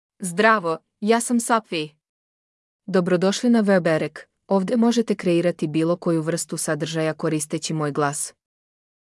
FemaleSerbian (Cyrillic, Serbia)
Sophie — Female Serbian AI voice
Voice sample
Female